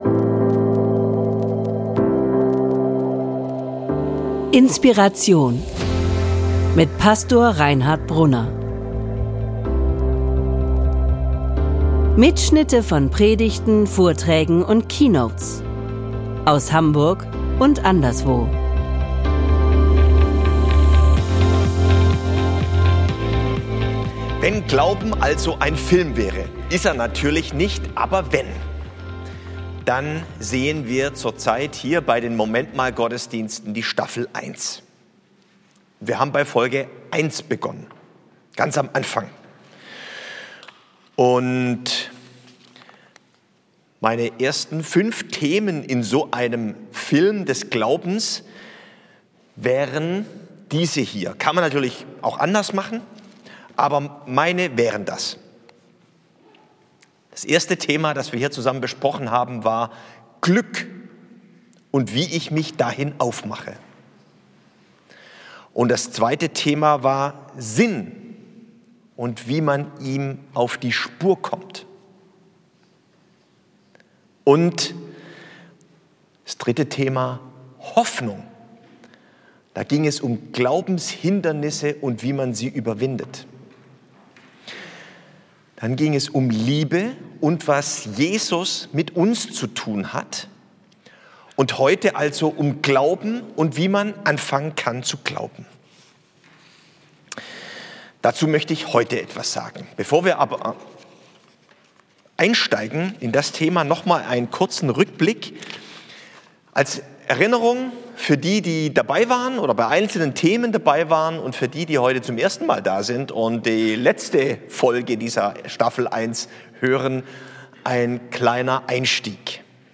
Glauben Staffel 1, Folge 5: Glaube - und anfangen, Gott zu vertrauen ~ INSPIRATION - Predigten und Keynotes Podcast